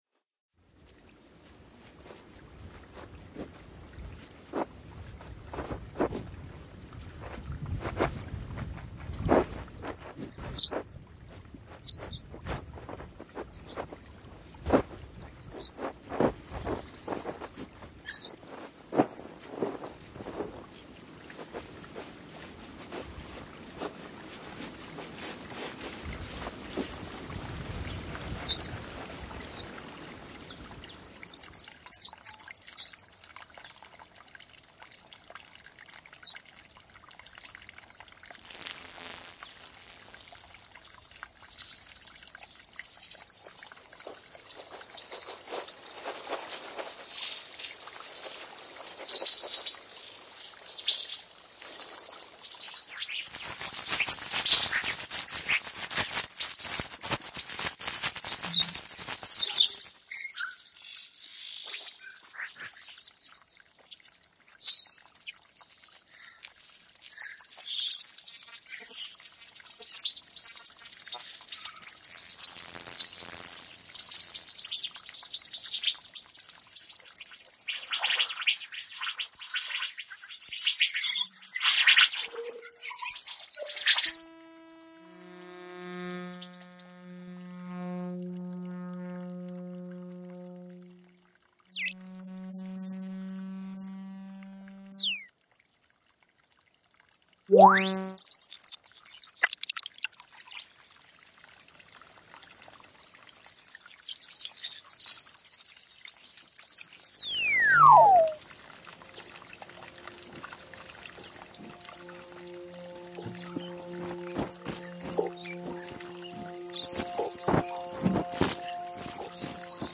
Divagações sonoras.